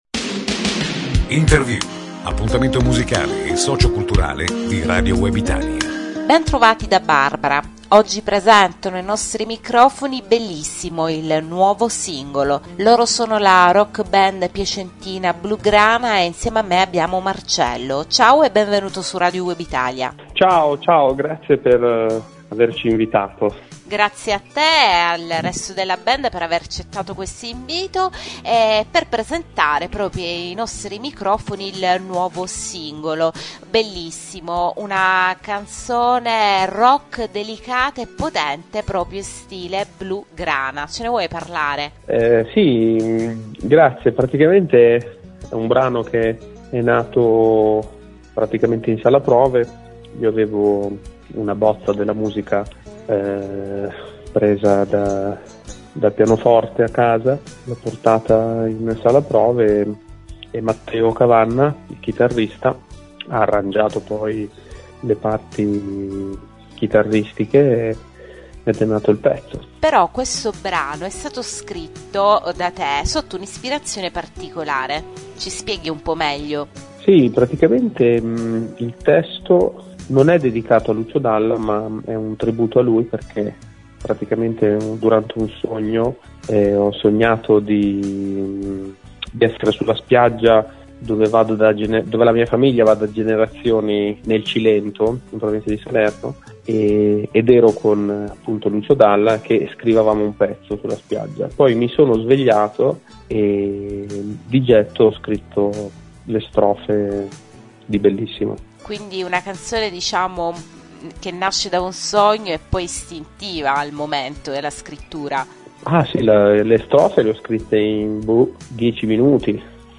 blugrana-intervista.mp3